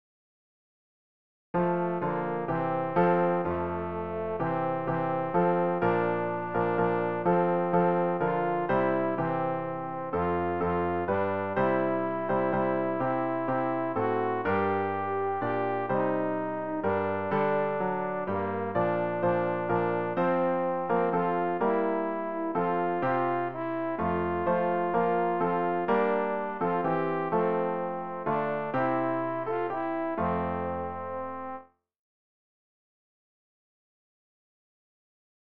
Übehilfen für das Erlernen von Liedern